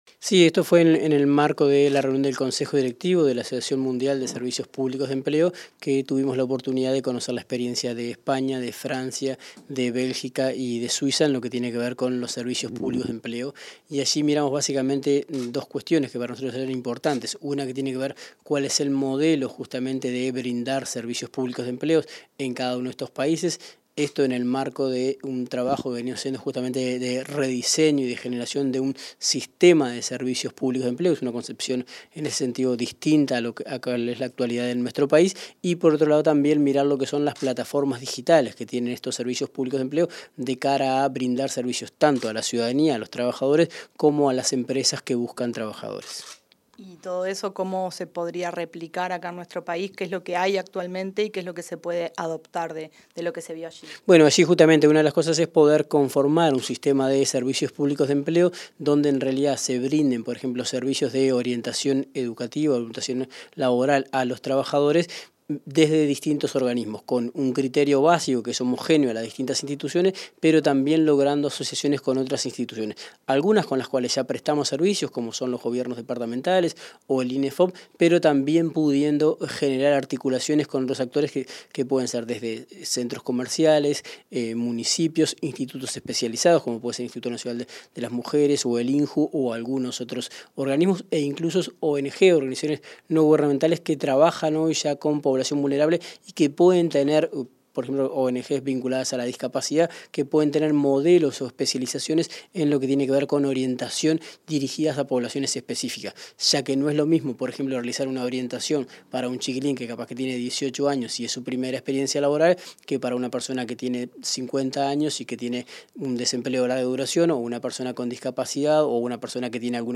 Entrevista al director nacional de Empleo, Daniel Pérez